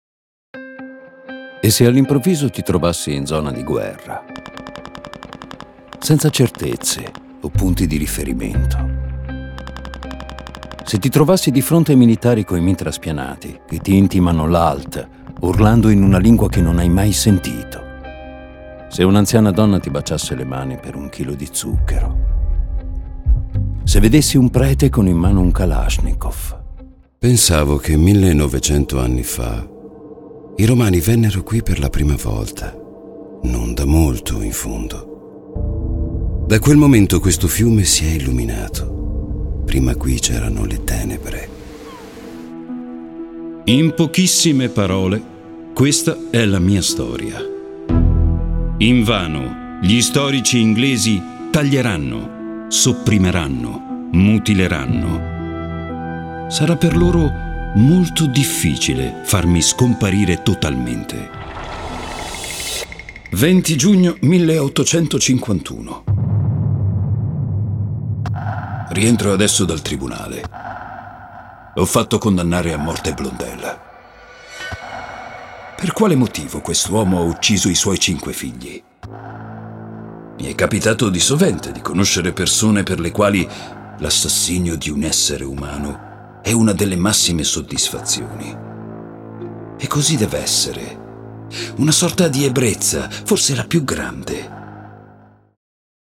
Male
Authoritative, Character, Confident, Cool, Deep, Warm, Versatile
Neutral Italian with perfect pronunciation and Italian dialect inflections.
Microphone: AKG C414 XL 2 - Rhode NT2 vintage